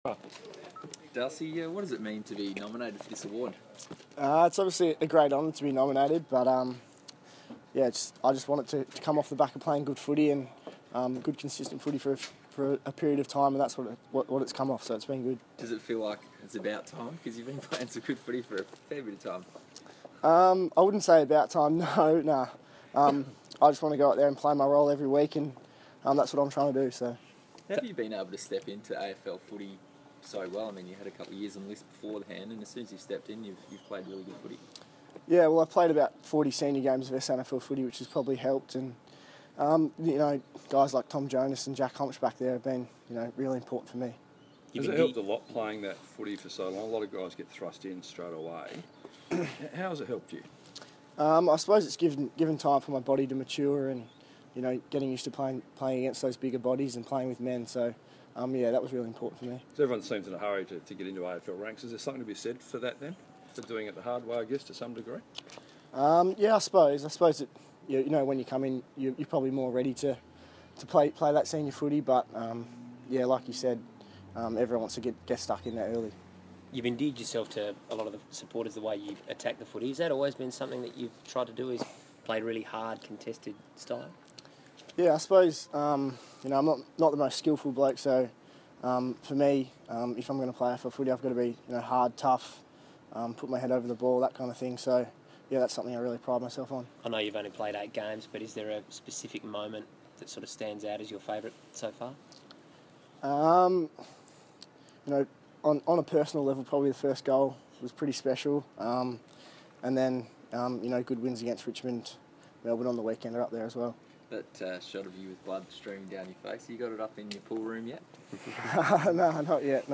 Round 10 NAB rising star Darcy Byrne-Jones talks to media.